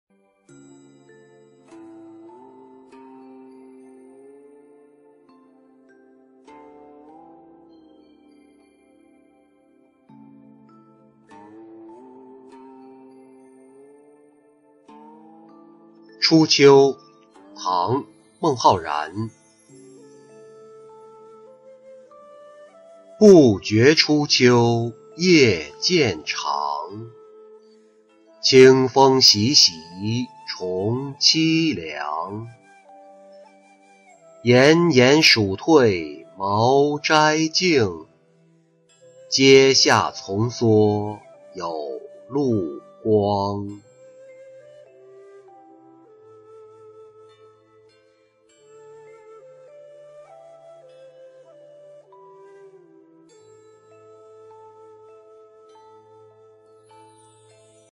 初秋-音频朗读